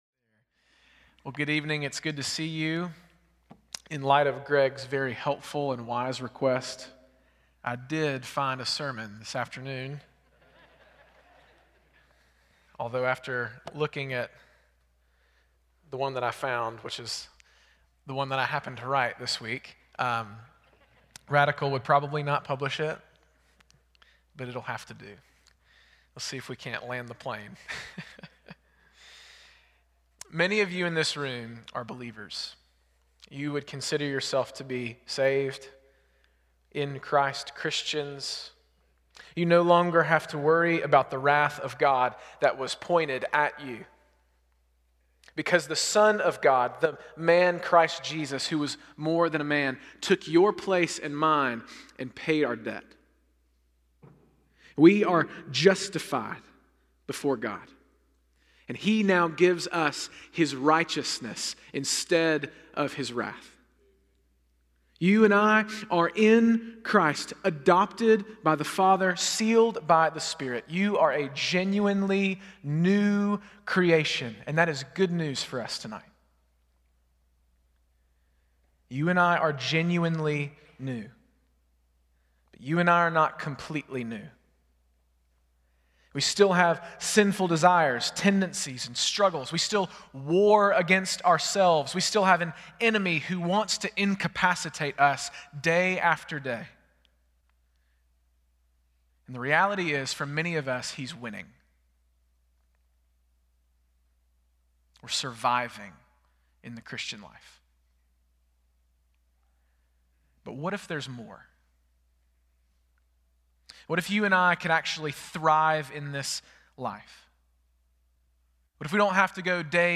Stand Alone Sermons
Service Type: Sunday Evening